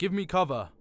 Barklines Combat VA